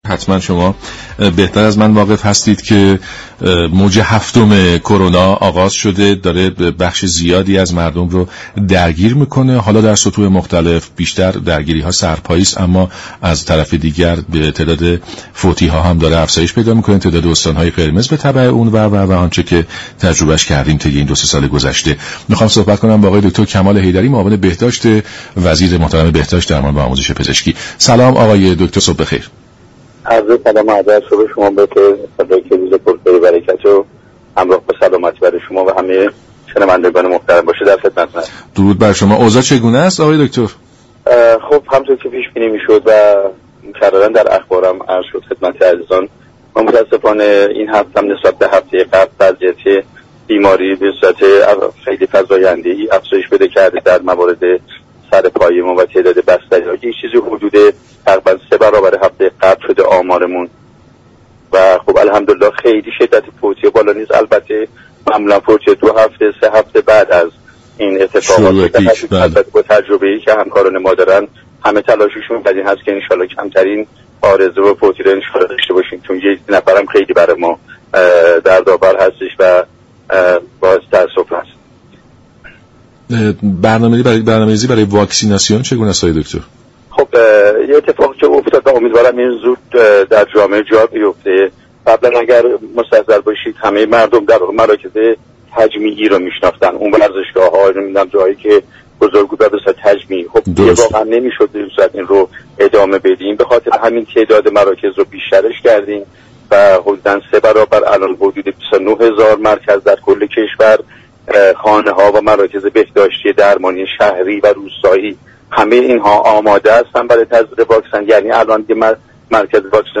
به گزارش شبكه رادیویی ایران، كمال حیدری معاون بهداشت وزیر بهداشت در برنامه «سلام صبح بخیر» رادیو ایران از شیوع موج هفتم ویروس كرونا در كشور خبر داد و گفت: این هفته تعداد بیماران بستری و سرپایی نسبت به هفته گذشته افزایش زیادی داشته و به رشد سه برابری رسیده است.